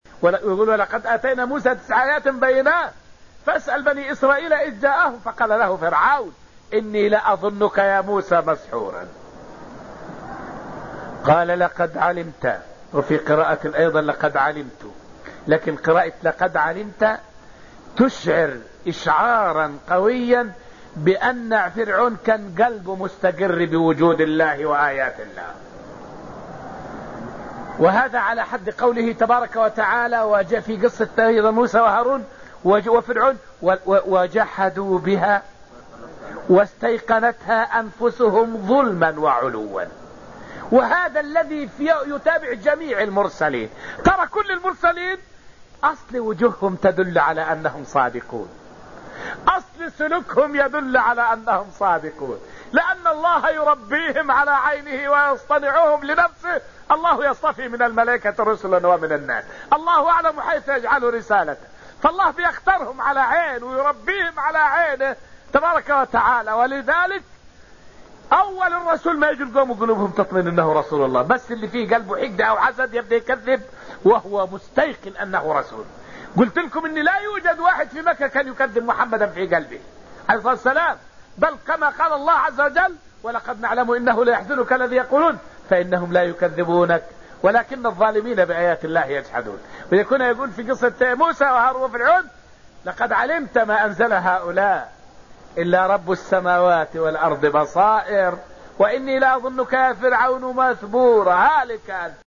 فائدة من الدرس السابع من دروس تفسير سورة القمر والتي ألقيت في المسجد النبوي الشريف حول جحود الكافرين نبوة الأنبياء.